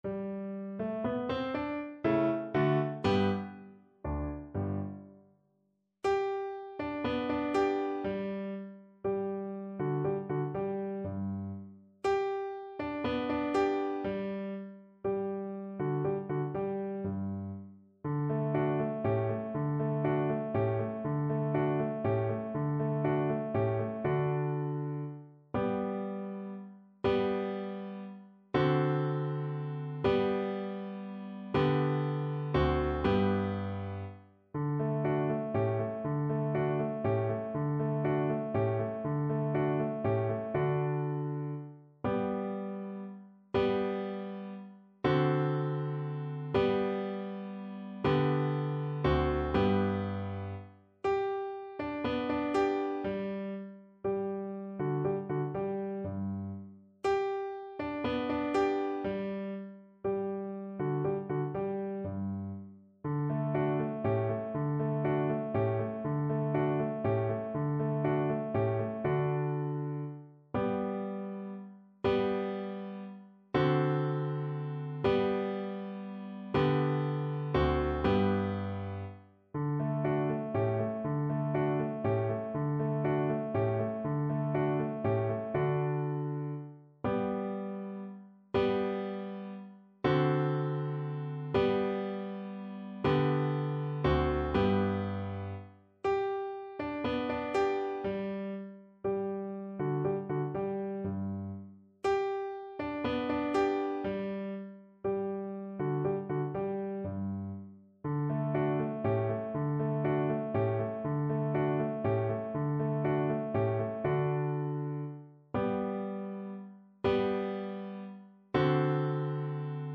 kolęda: Bracia, patrzcie jeno (na wiolonczelę i fortepian)
Symulacja akompaniamentu